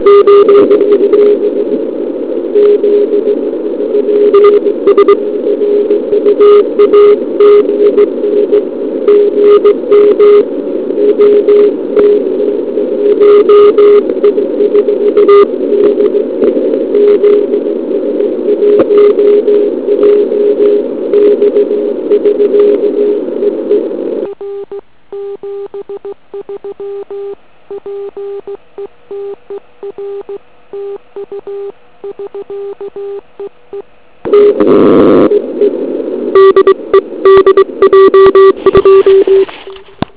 Typická Greyline situace. Všimněte si typického "nárazového" QSB.